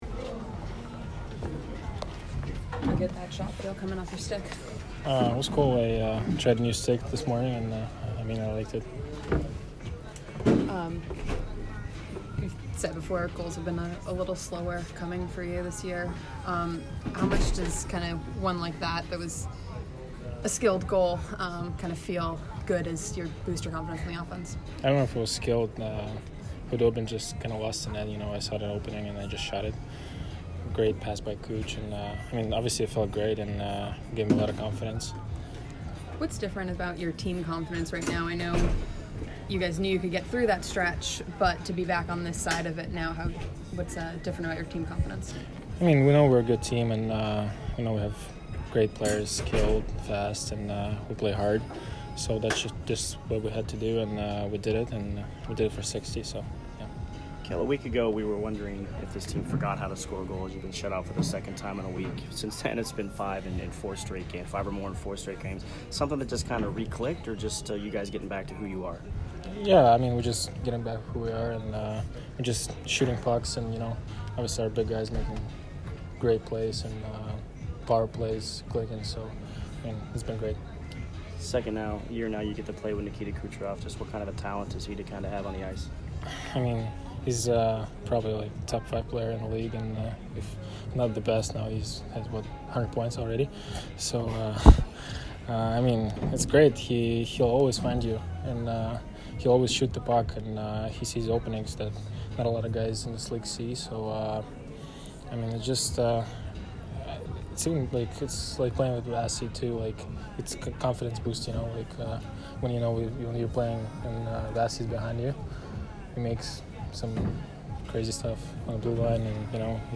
Mikhail Sergachev post-game 2/14